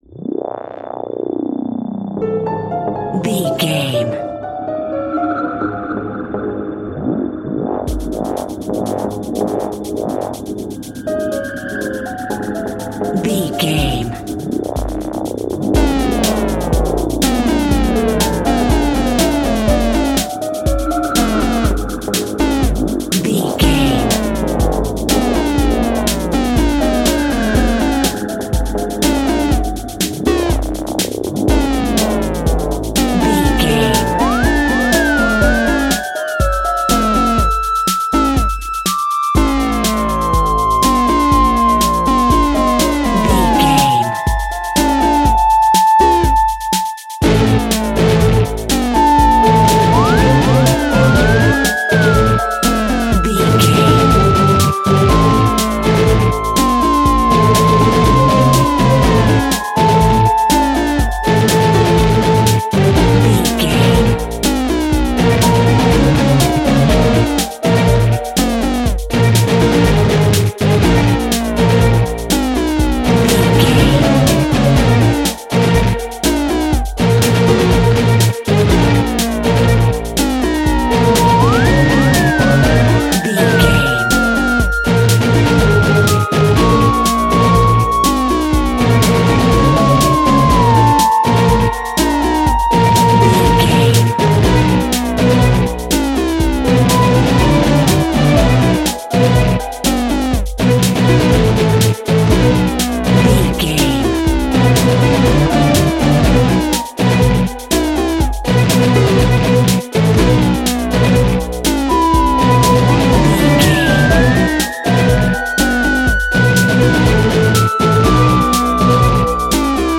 In-crescendo
Thriller
Aeolian/Minor
ominous
dark
suspense
haunting
eerie
synthesizer
drum machine
piano
strings
ambience
pads